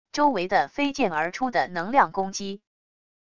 周围的飞溅而出的能量攻击wav音频